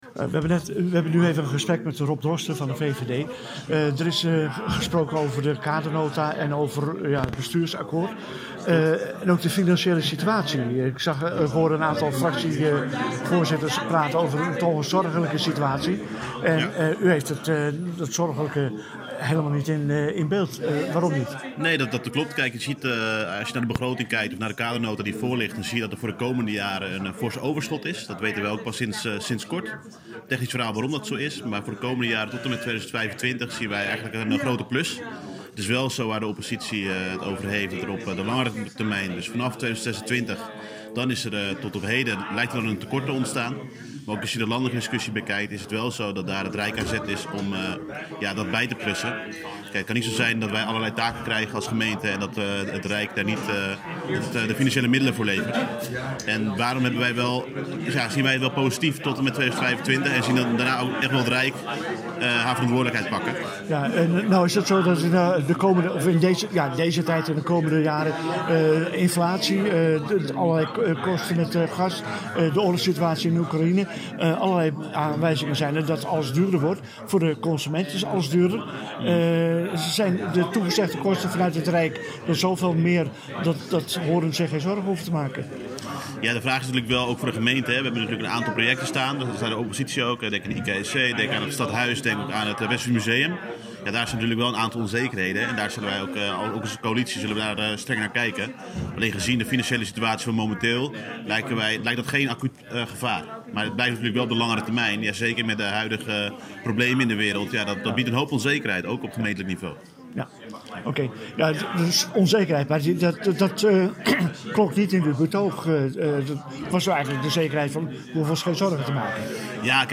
Raadsvergadering 21 juni 2022 (Audio interview met Arnica Gortzak (PvdA), Rob Droste (VVD), wethouder René Assendelft (HL)